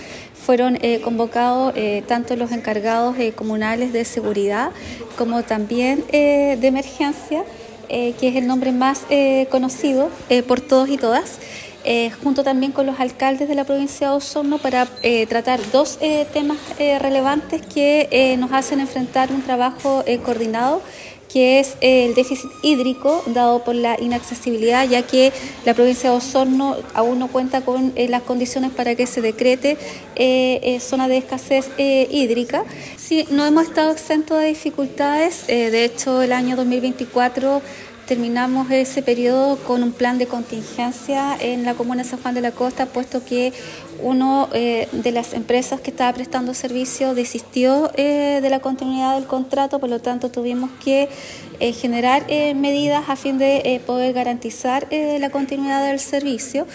La delegada presidencial provincial, Claudia Pailalef, destacó la importancia de este espacio de coordinación, subrayando las lecciones aprendidas del año anterior, especialmente en cuanto a la entrega de agua potable mediante camiones aljibes.